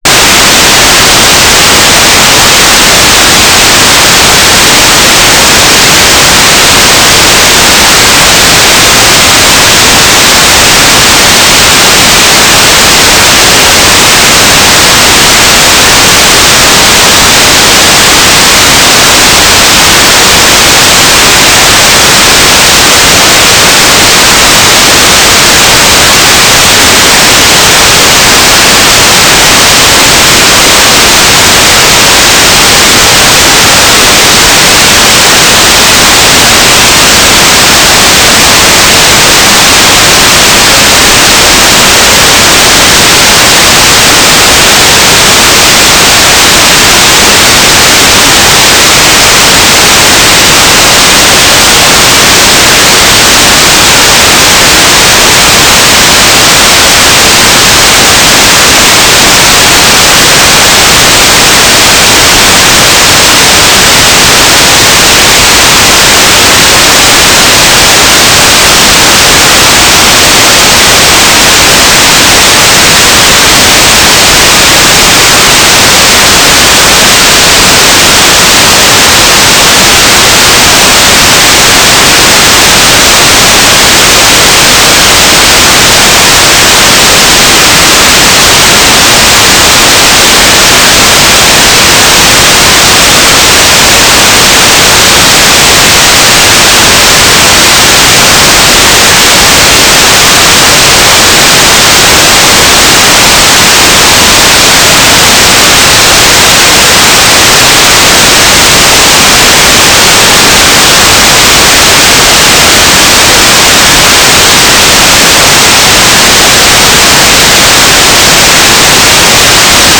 "transmitter_description": "Mode U - GMSK9k6 - AX.25 G3RUH - Telemetry",